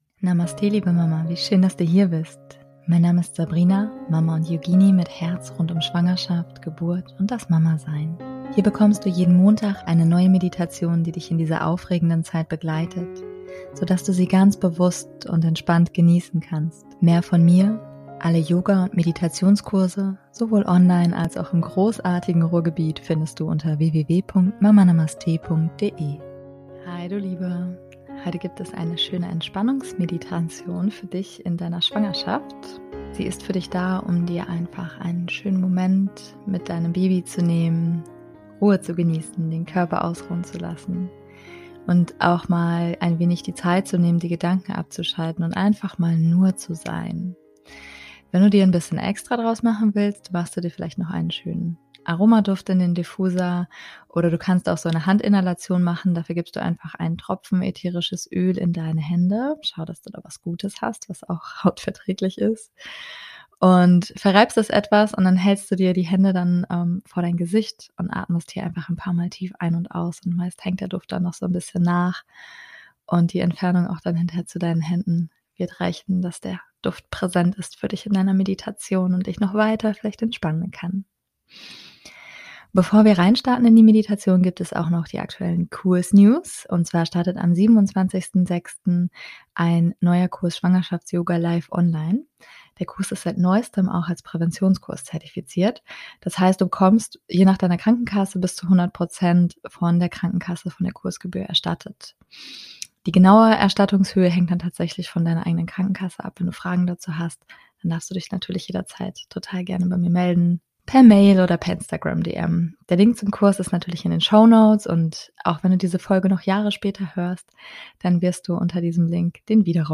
Heute gibt es eine schöne Entspannungsmeditation für dich. Sie ist für dich da, um dir in deiner Schwangerschaft einfach einen schönen Moment für dich und dein Baby zu nehmen, Ruhe zu genießen, den Körper ausruhen zu lassen.